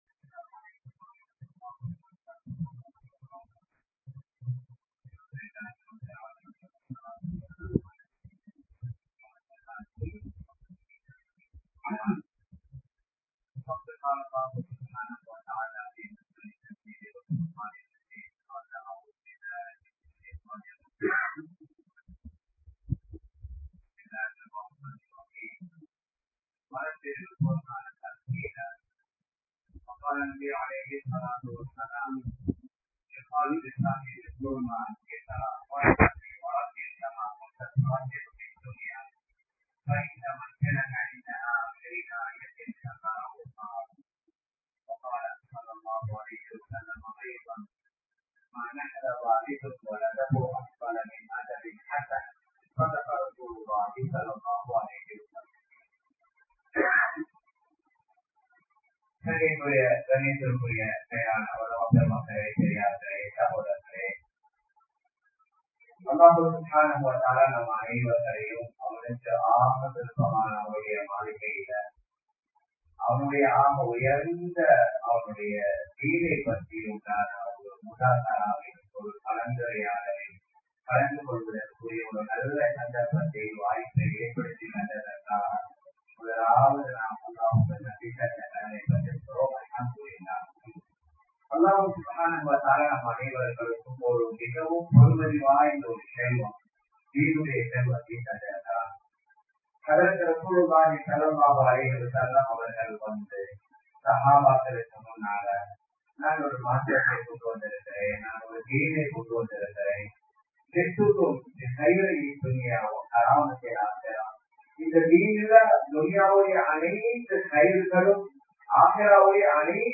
Dheenai Padikka Nearam Kodungal (தீணை படிக்க நேரம் கொடுங்கள்) | Audio Bayans | All Ceylon Muslim Youth Community | Addalaichenai
Ayesha Jamiya Masjidh